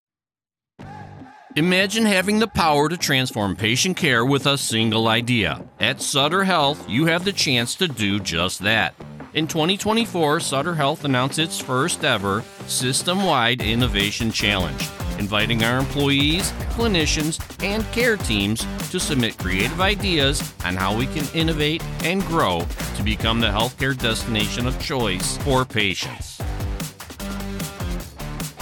American Male Voice Over Artist
I work out of a broadcast quality home studio with professional recording equipment and a quick turnaround time!